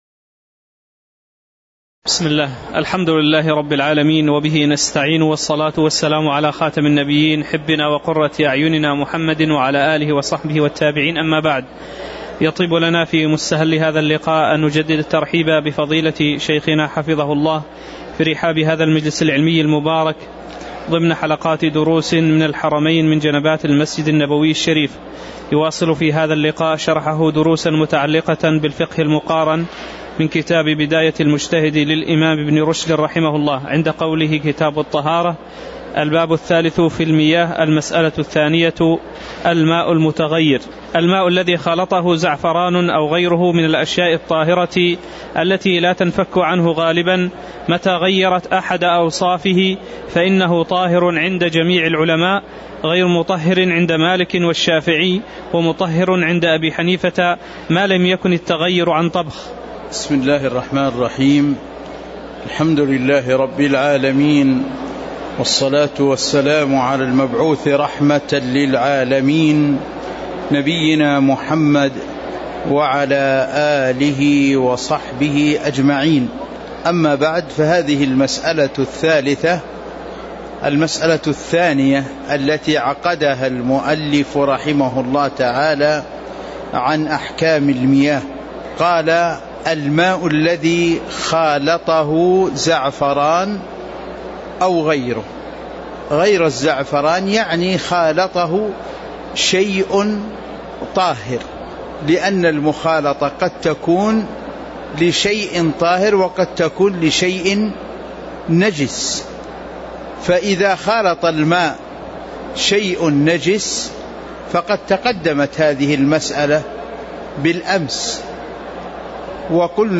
تاريخ النشر ٢٨ محرم ١٤٤٠ هـ المكان: المسجد النبوي الشيخ